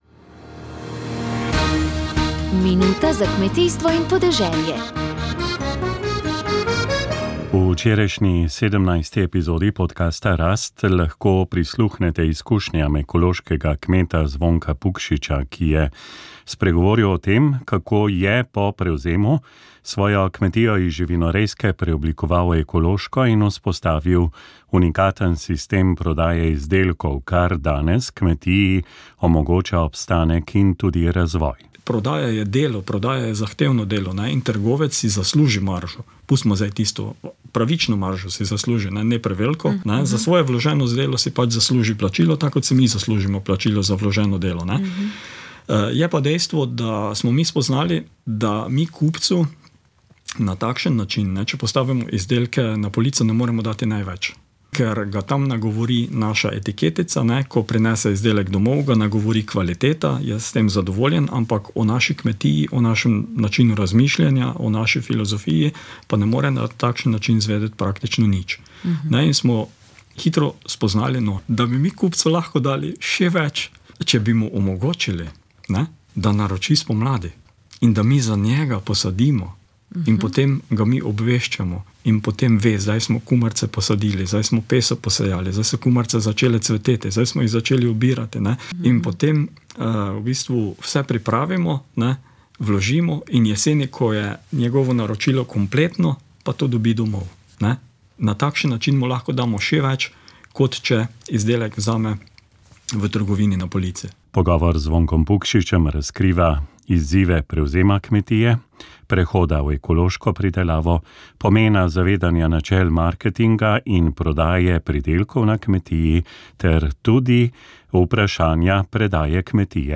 Duhovni nagovor
Za praznik Binkošti je duhovni nagovor pripravil ljubljanski nadškof msgr. dr. Anton Stres.